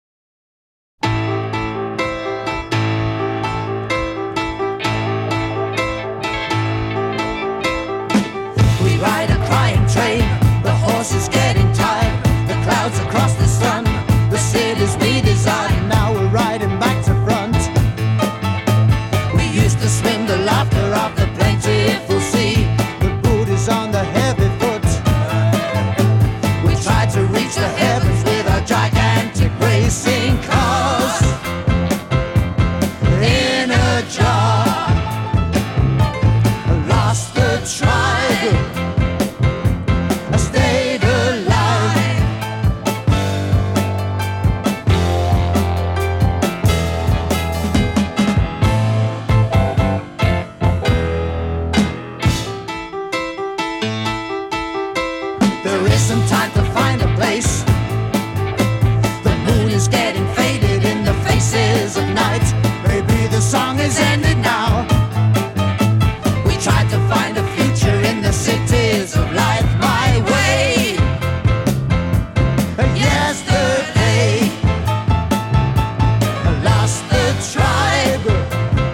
A fusion of jazz, rock and Afro influences